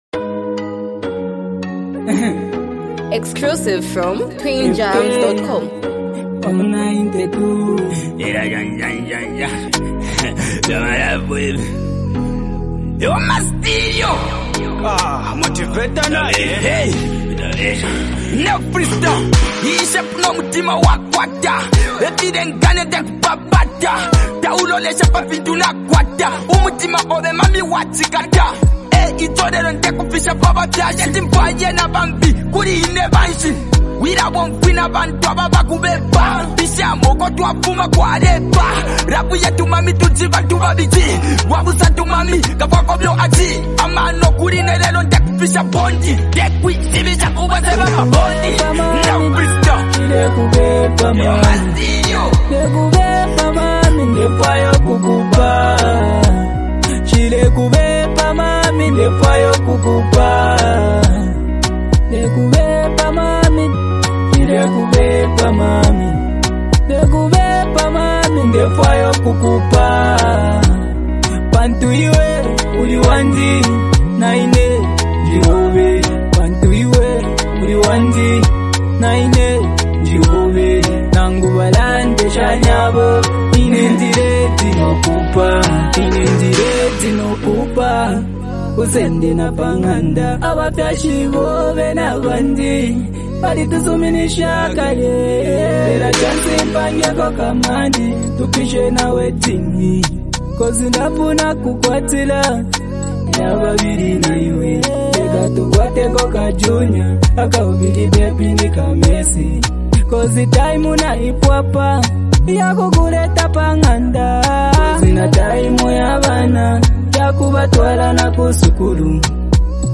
heartfelt and emotionally driven song
delivers his verses with a calm yet passionate approach
melodic and soulful hook